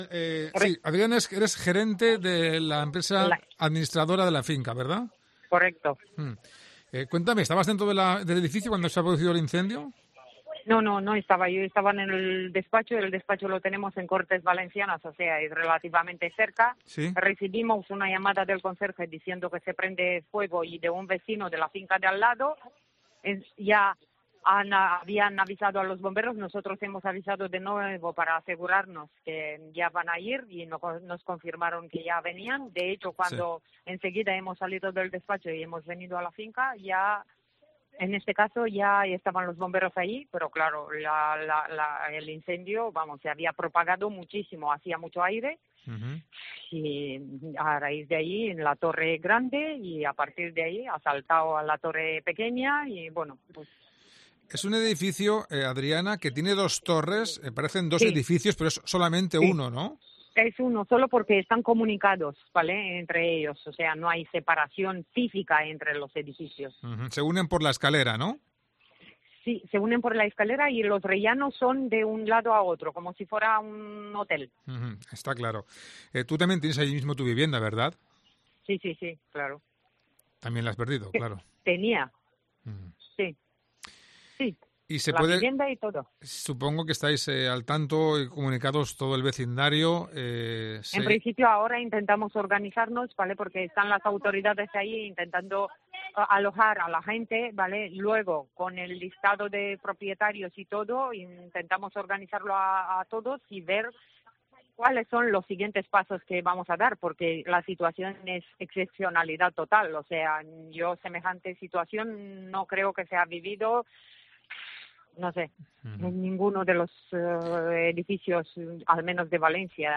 INCENDIO